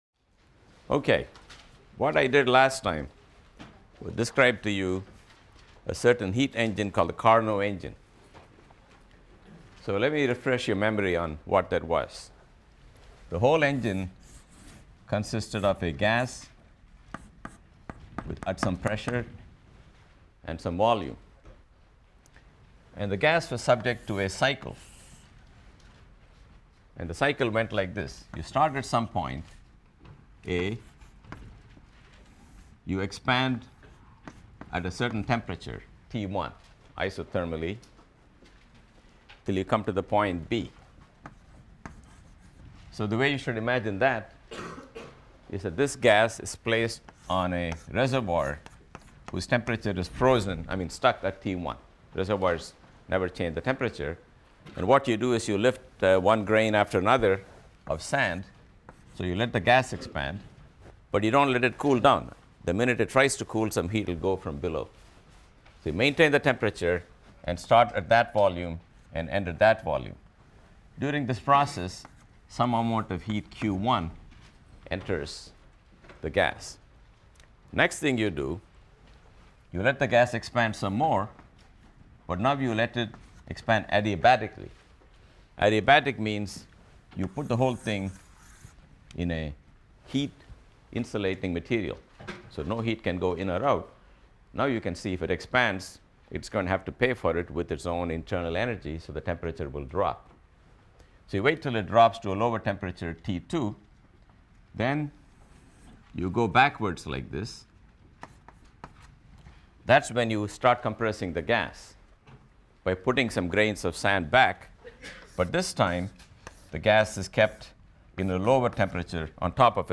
PHYS 200 - Lecture 24 - The Second Law of Thermodynamics (cont.) and Entropy | Open Yale Courses